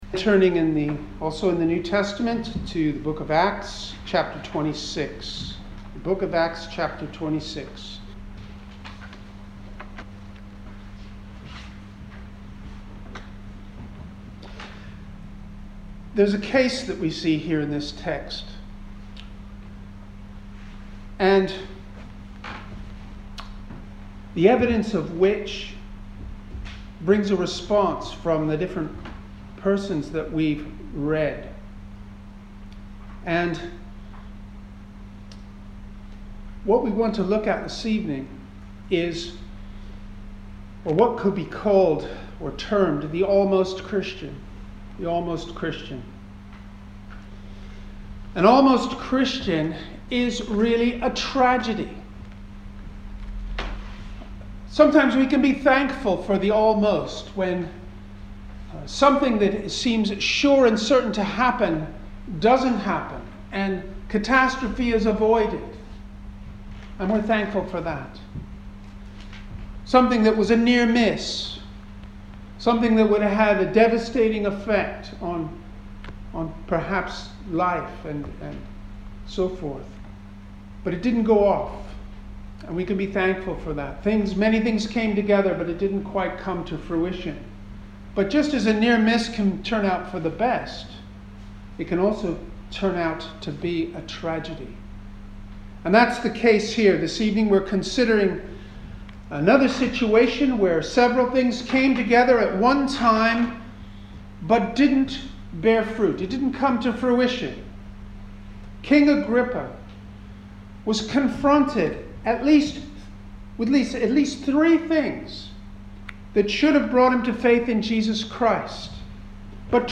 Service Type: Sunday Evening
Single Sermons